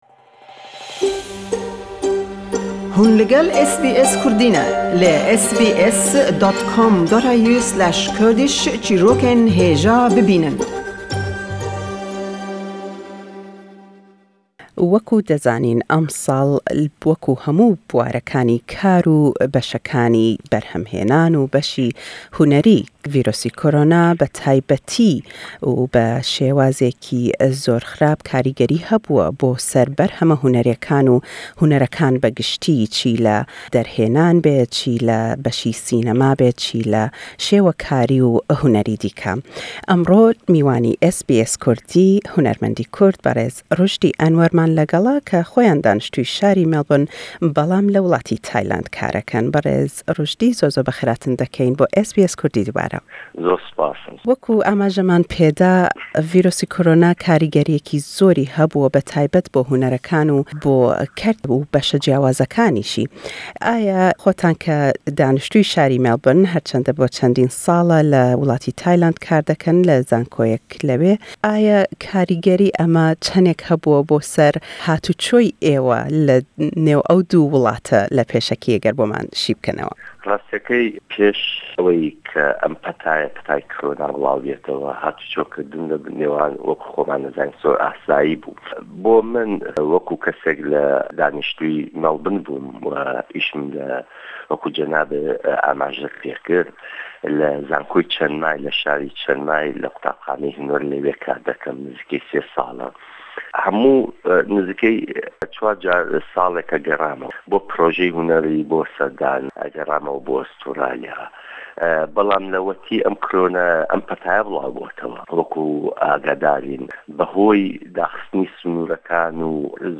Le em lêdwane da